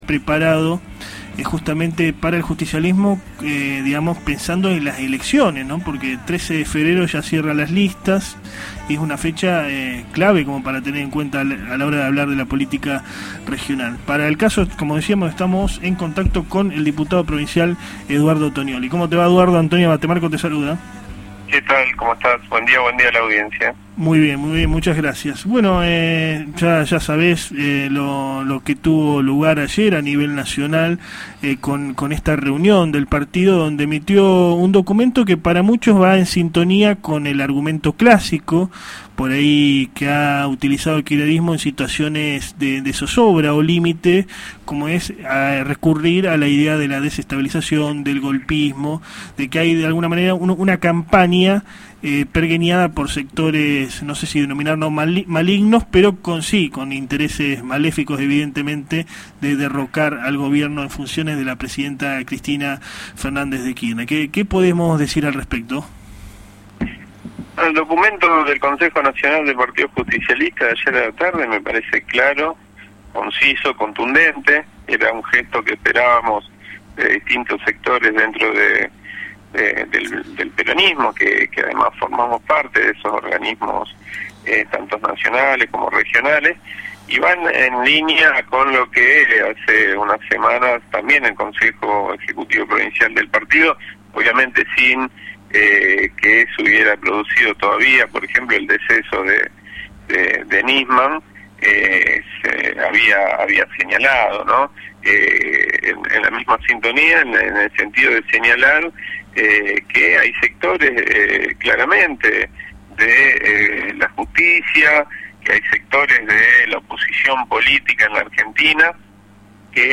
EDUARDO TONIOLLI AUDIO ENTREVISTA
Media Mañana, programa que se emite de lunes a viernes de 9 a 11 hs, por FM “El Cairo”, 105.7 de Rosario, entrevistó a Eduardo Toniolli, diputado provincial del Movimiento Evita dentro del Frente Para la Victoria.